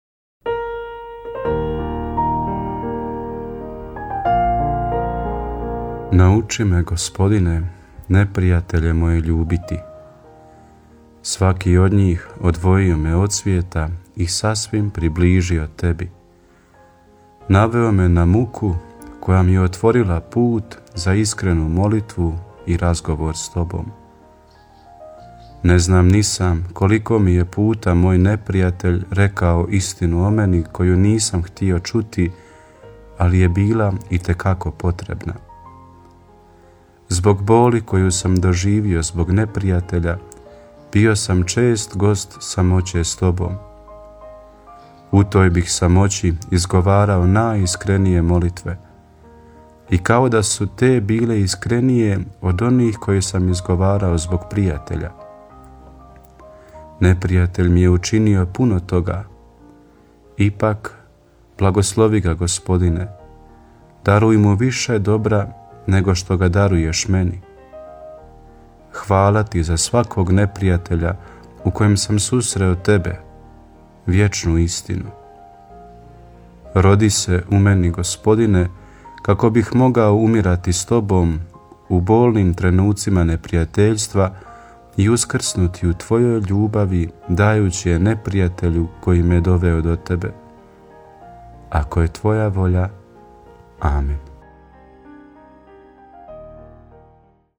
Emisije priređuju svećenici i časne sestre u tjednim ciklusima.